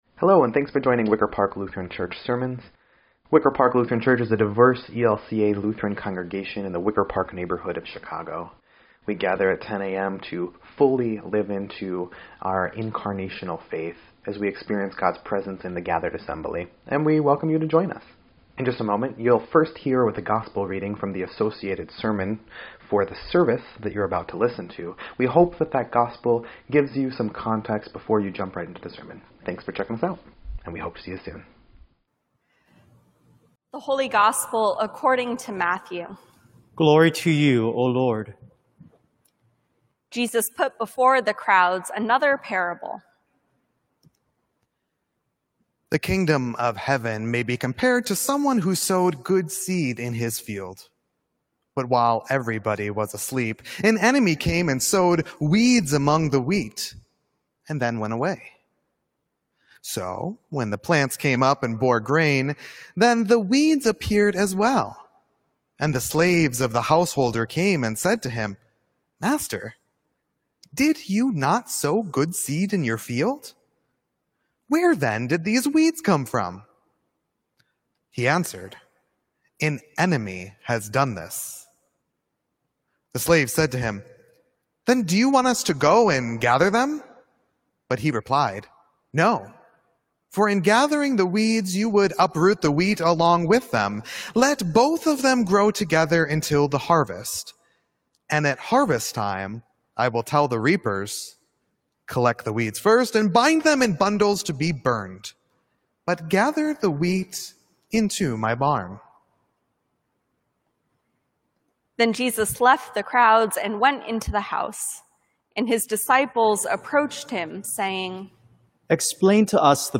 7.19.20-Sermon.mp3